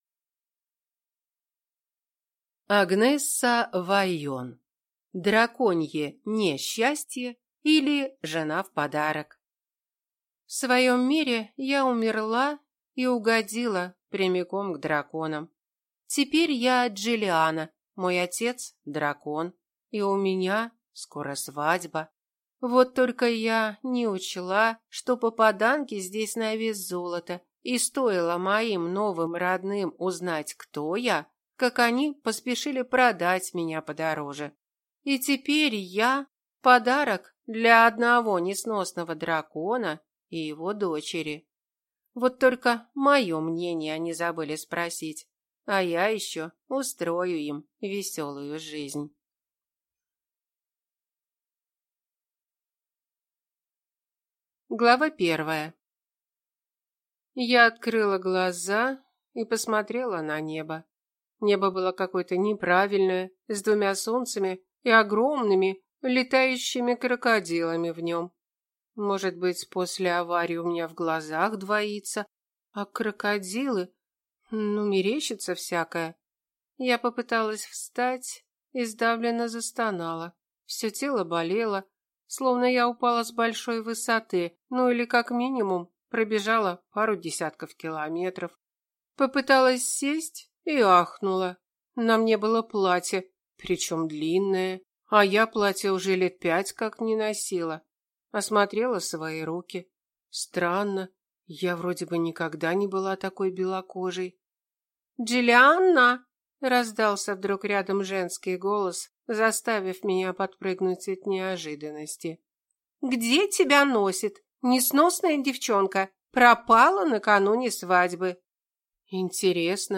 Аудиокнига Драконье (не)счастье, или жена в подарок | Библиотека аудиокниг
Прослушать и бесплатно скачать фрагмент аудиокниги